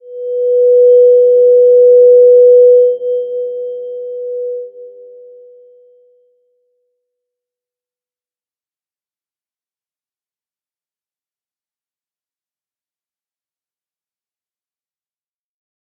Slow-Distant-Chime-B4-p.wav